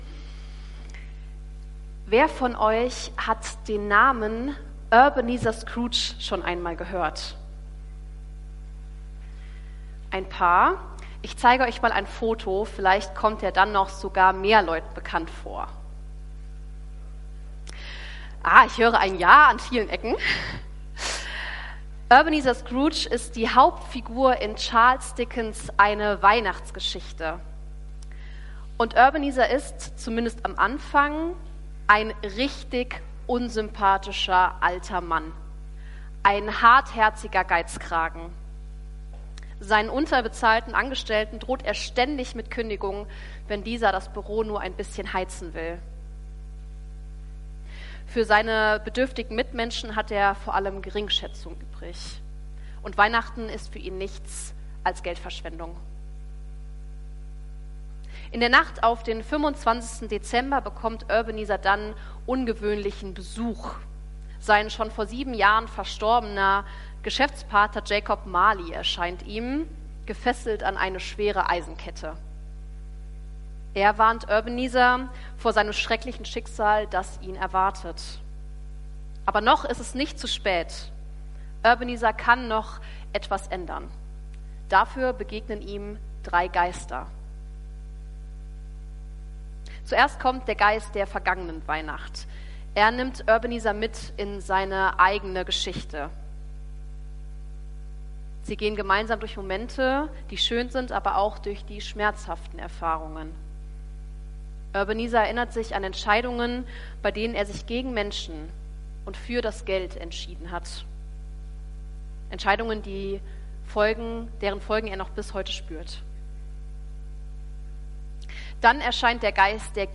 Predigt vom 25.12.2025